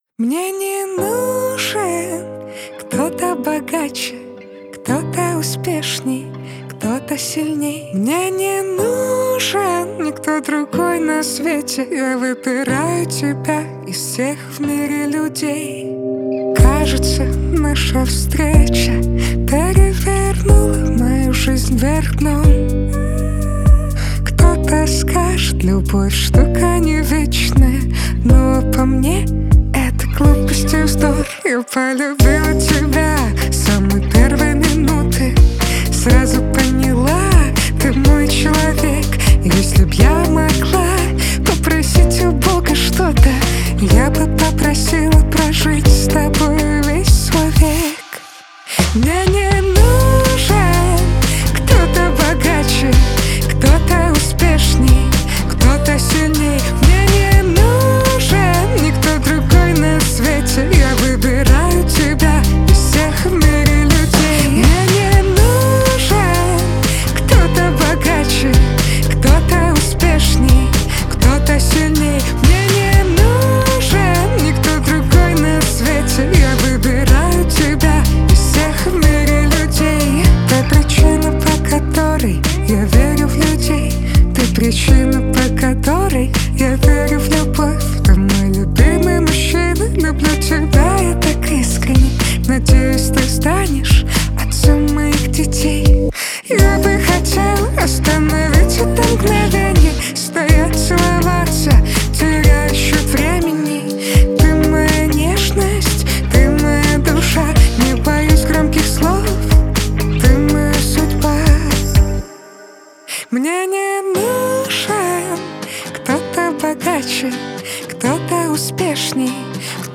Категория Рэп